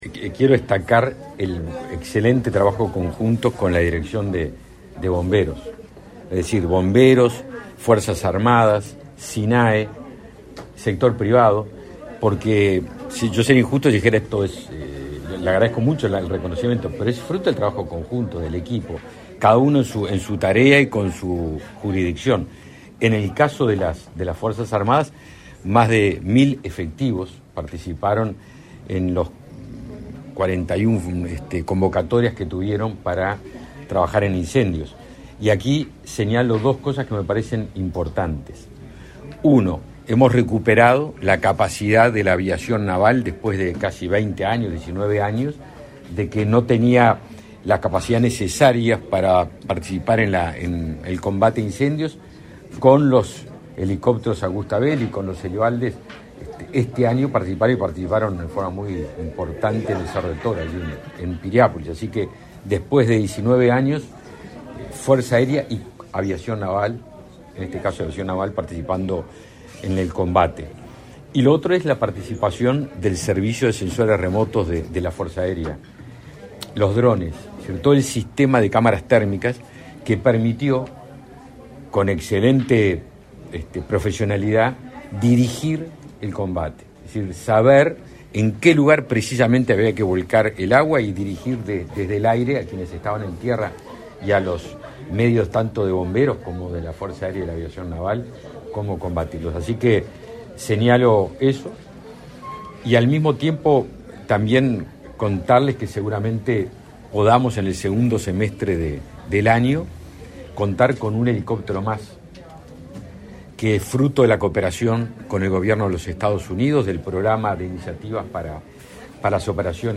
Declaraciones a la prensa del ministro de Defensa Nacional, Javier García
Tras el evento, el jerarca realizó declaraciones a la prensa.